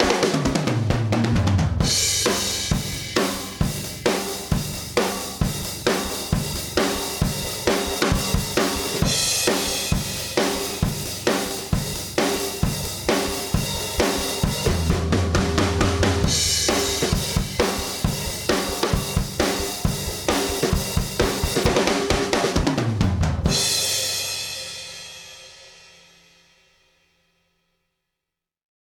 BLACK 76_Drums_Heavy_Compression.mp3